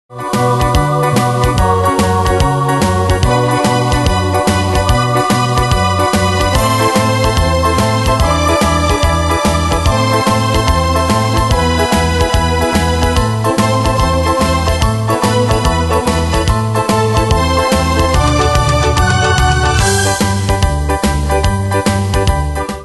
Demos Midi Audio
Style: Retro Année/Year: 1963 Tempo: 145 Durée/Time: 2.06
Danse/Dance: Rock Cat Id.
Pro Backing Tracks